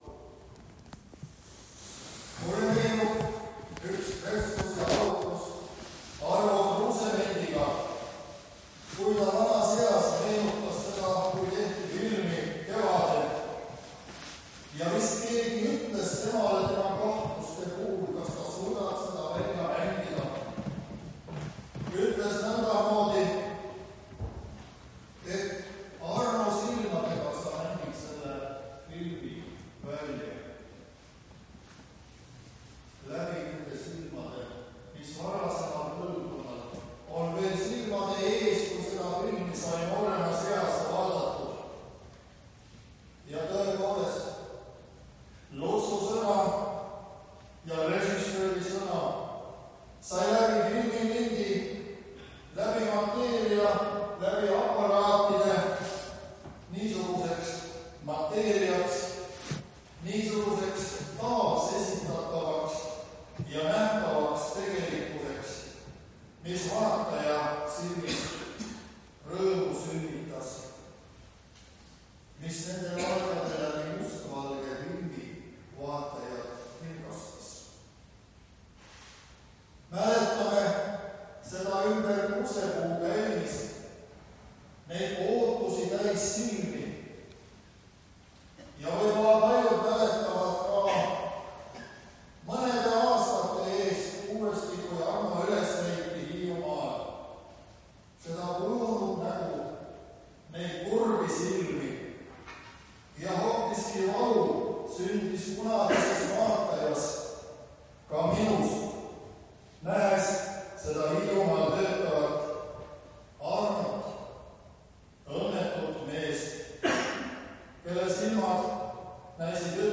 Usuteaduse Instituudi jõulujumalateenistusel